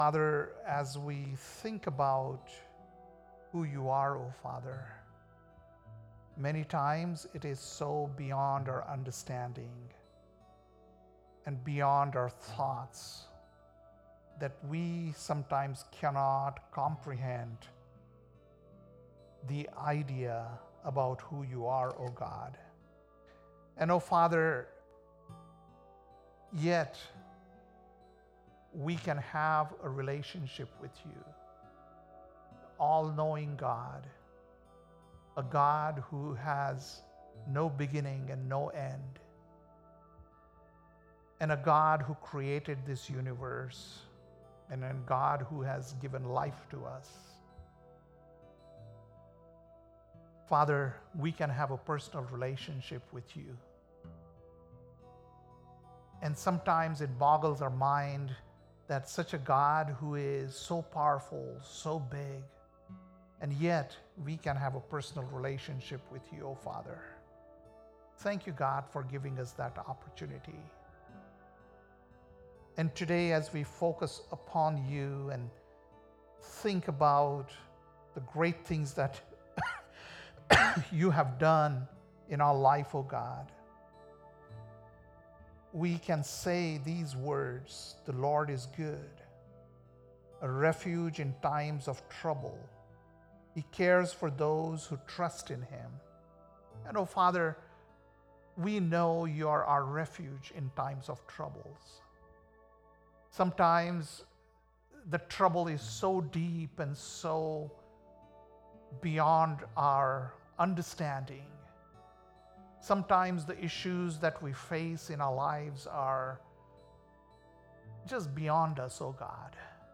September 15th, 2024 - Sunday Service - Wasilla Lake Church